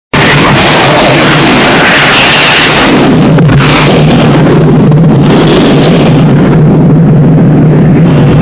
BSG FX - Viper Launch 01 Download Picture
BSG_FX-Viper_Launch_01.mp3